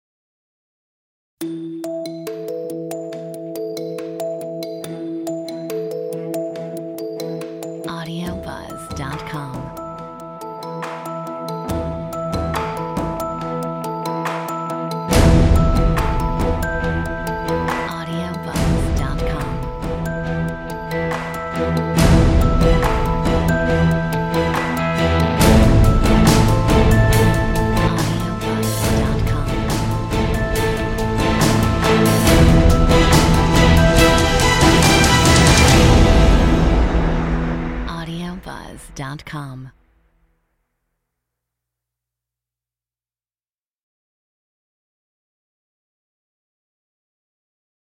Metronome 70